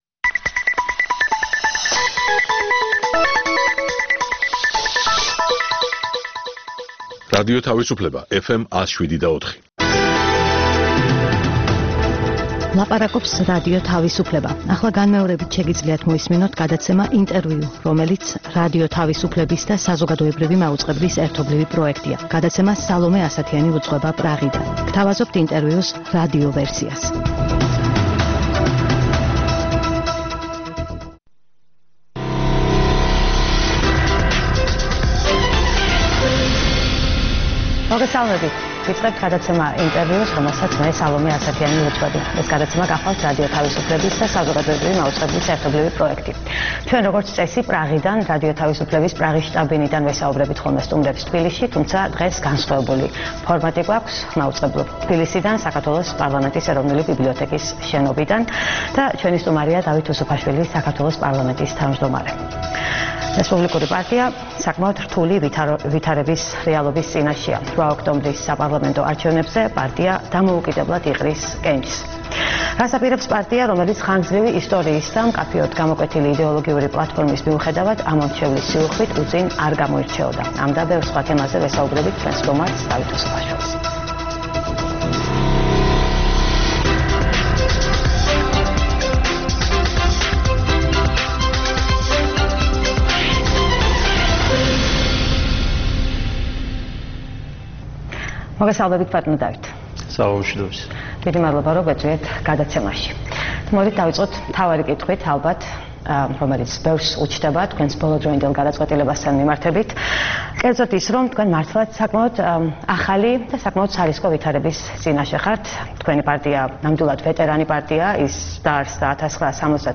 ყოველკვირეული გადაცემა „ინტერVIEW“ არის რადიო თავისუფლებისა და საზოგადოებრივი მაუწყებლის ერთობლივი პროექტი. მასში მონაწილეობისთვის ვიწვევთ ყველას, ვინც გავლენას ახდენს საქართველოს პოლიტიკურ პროცესებზე. „ინტერview“ არის პრაღა-თბილისის ტელეხიდი,